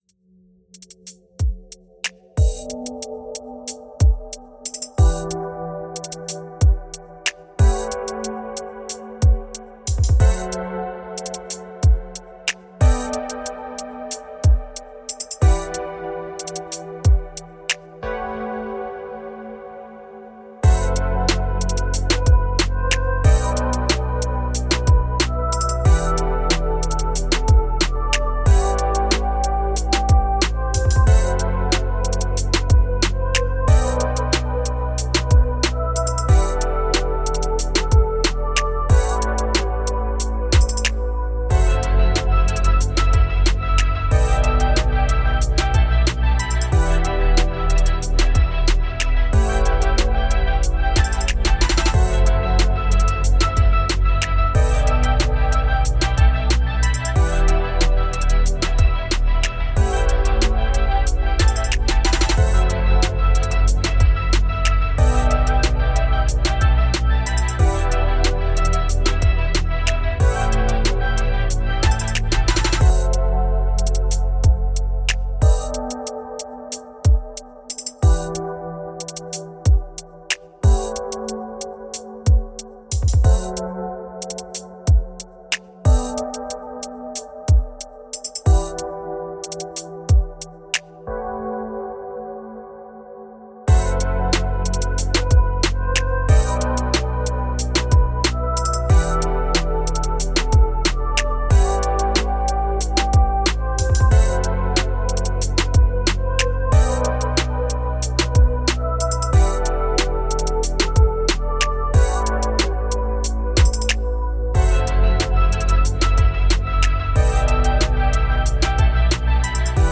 Musique chill libre de droit pour vos projets.